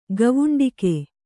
♪ gavuṇdiki